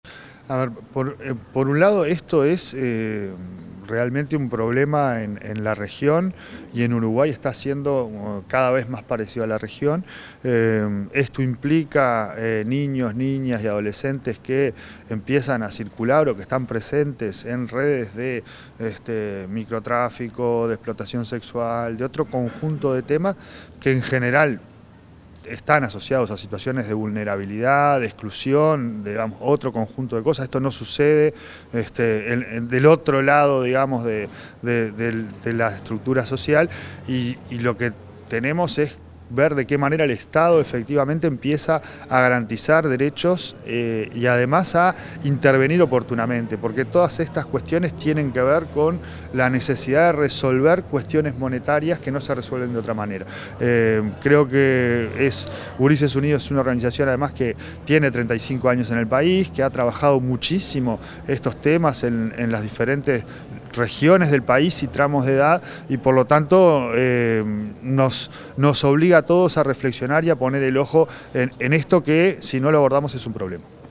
Declaraciones del presidente de ANEP, Pablo Caggiani
Declaraciones del presidente de ANEP, Pablo Caggiani 13/06/2025 Compartir Facebook X Copiar enlace WhatsApp LinkedIn El presidente de la Administración Nacional de Educación Pública (ANEP), Pablo Caggiani, efectuó declaraciones a los medios de prensa tras participar en el conversatorio Infancias, Adolescencias y Crimen Organizado, convocado por la organización no gubernamental Gurises Unidos.